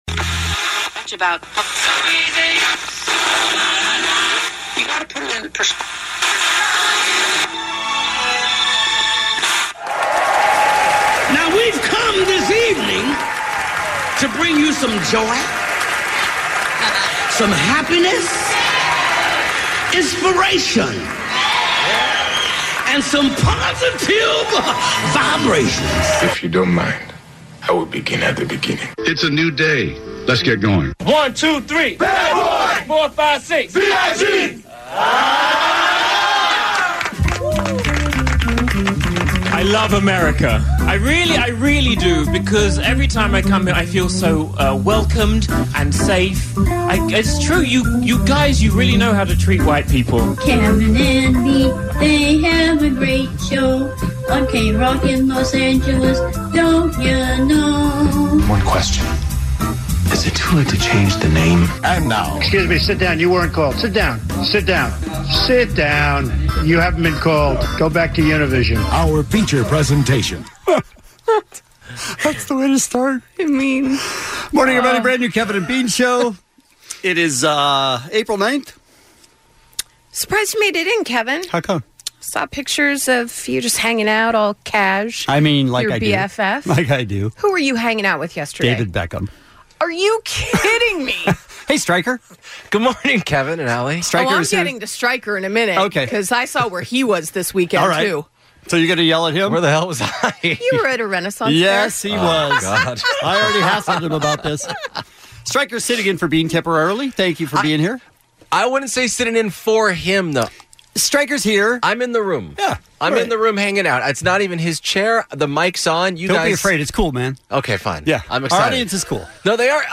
Doug Benson In Studio.